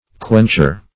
Quencher \Quench"er\, n.